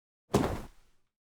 Tk Block Impact.wav